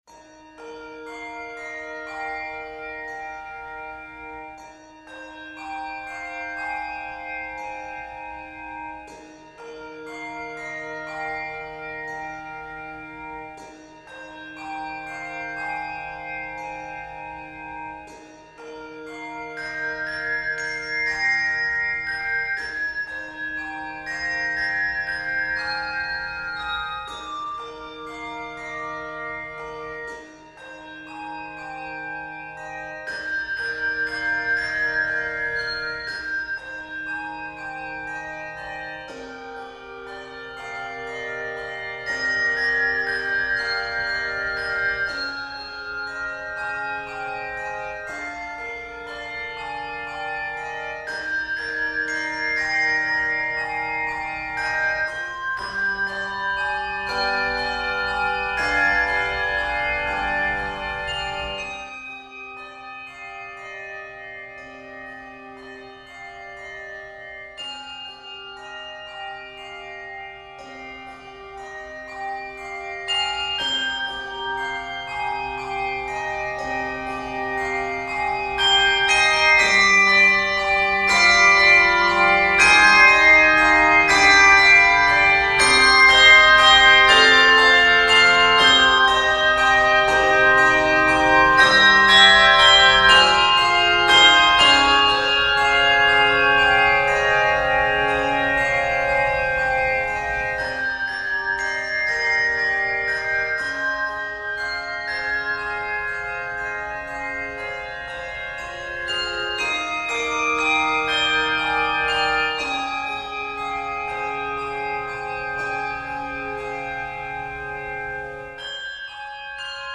Voicing: Handbells